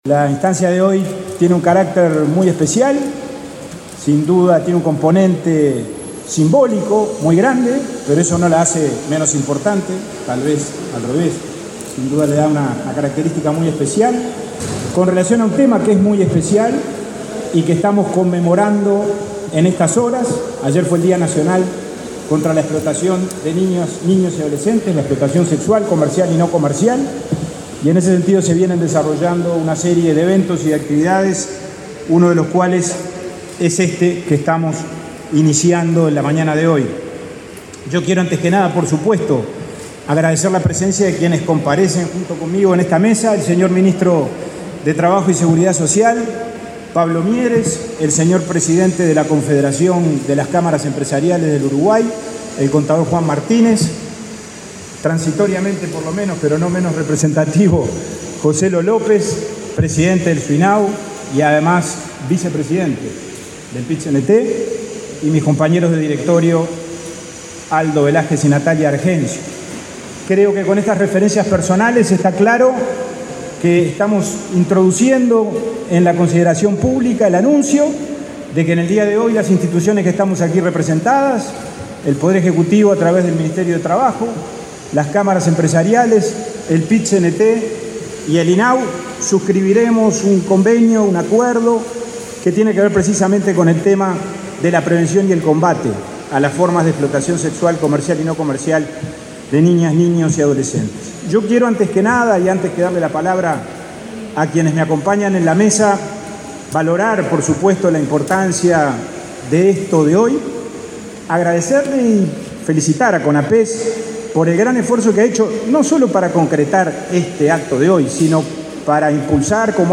Palabras del presidente de INAU y el ministro de Trabajo